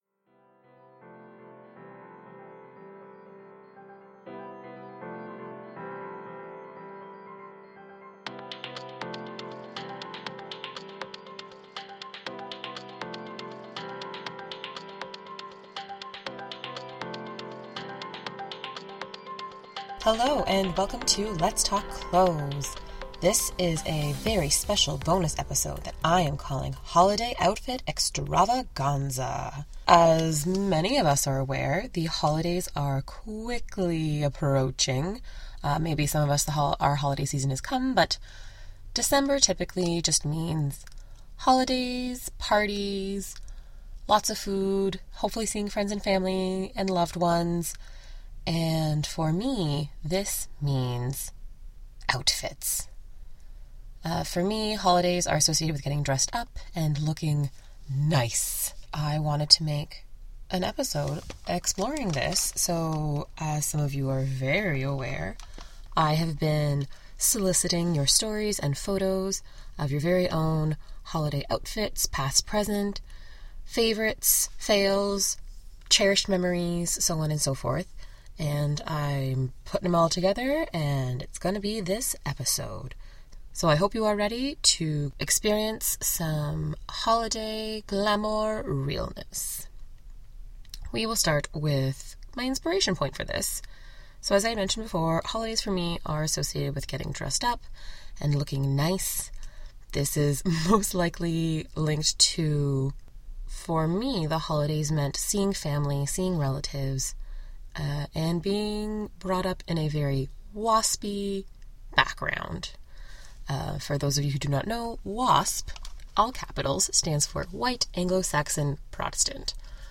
On A Very Special Episode of Let’s Talk Clothes it’s just me in the studio talking about holiday outfits — past, present, and future. In this episode we cover: memories from childhood as well as more current outfits, both listener sent-in as well as my own.